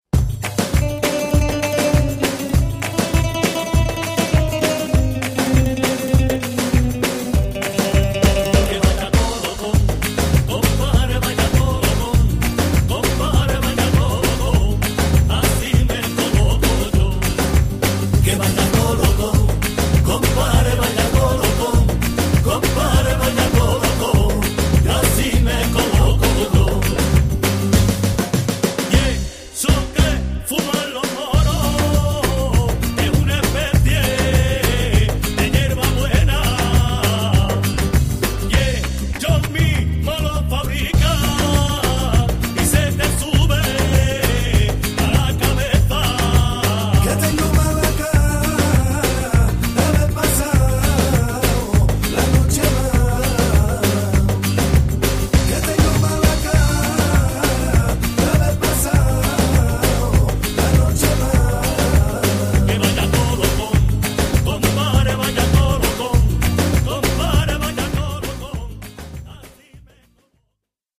最初から最後まで、彼らのルーツ音楽である『ルンバ・フラメンコ』に対する敬意・愛が溢れている！